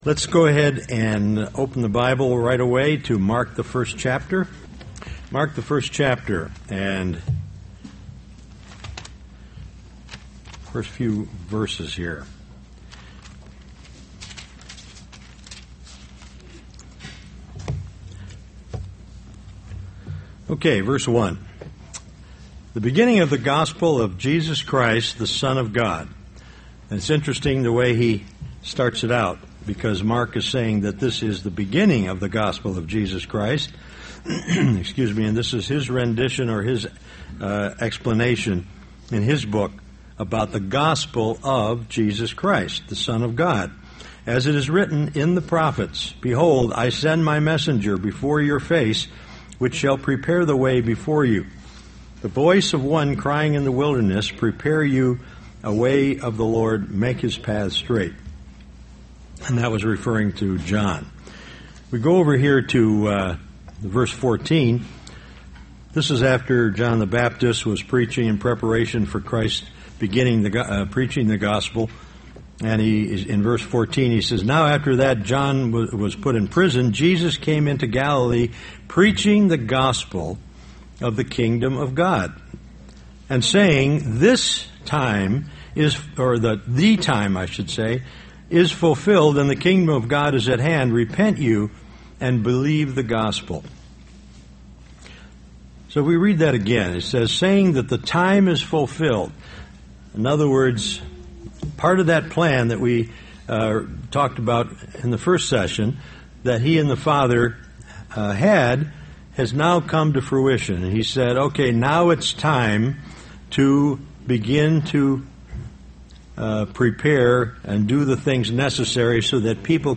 Why does God allow certain things to happen? This message was given as a Kingdom of God Bible seminar.
UCG Sermon Studying the bible?